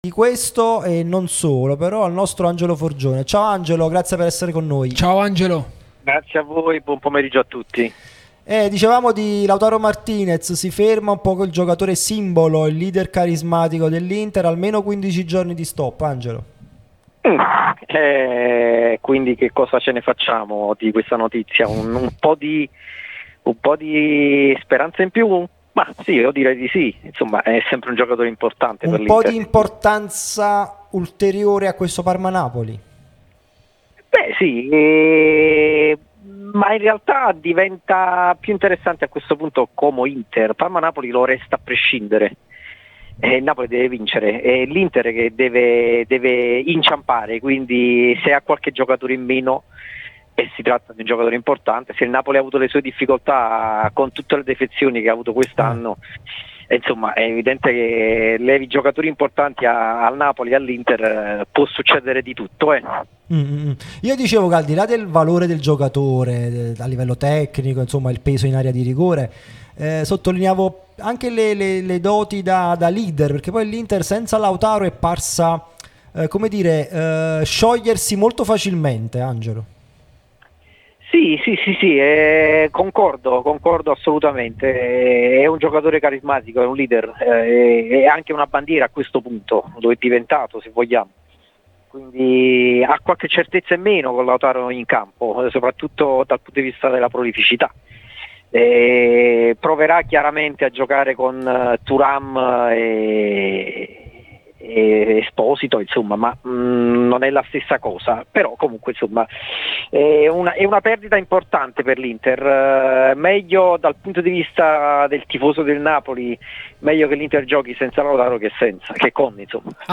Podcast Alisson o i Fab Four?